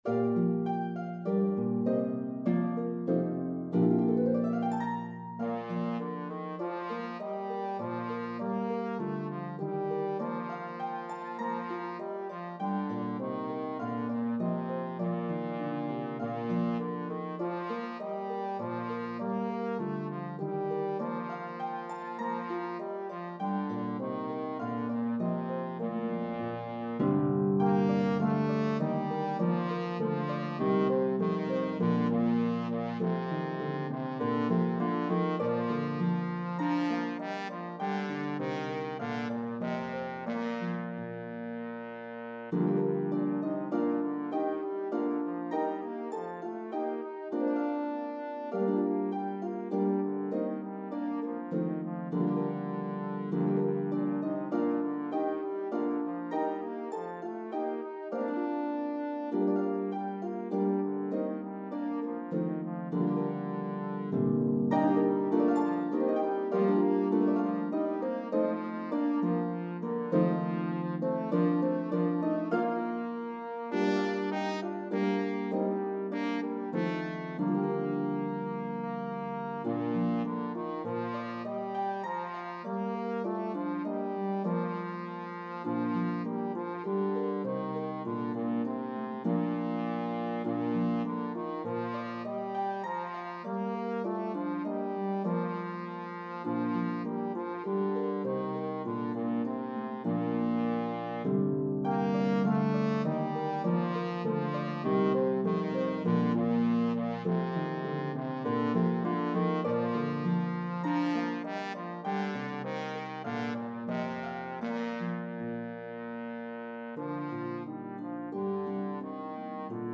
The exuberant French Carol